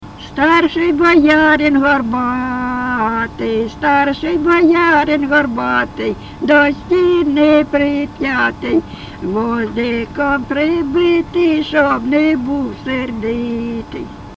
ЖанрВесільні
Місце записус. Привілля, Словʼянський (Краматорський) район, Донецька обл., Україна, Слобожанщина